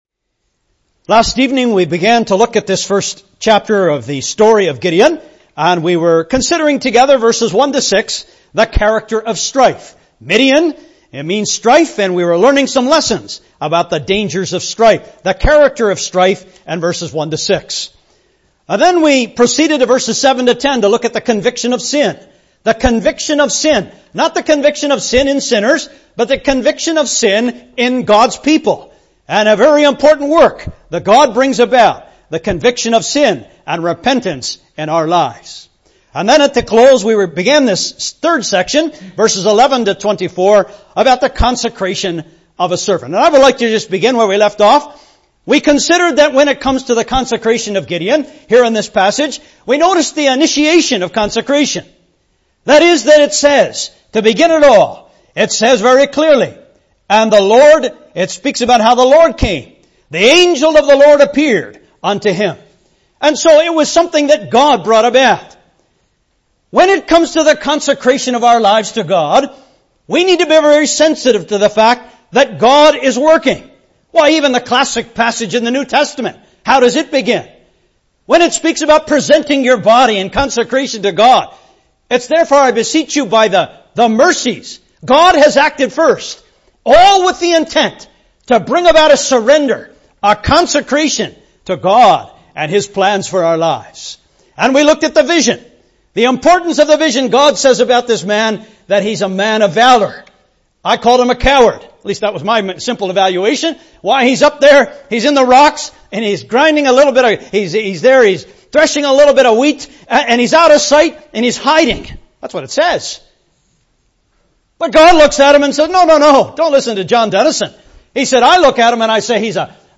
Included are some helpful insights into the issue of “putting out a fleece” (Message preached Oct 28th 2016)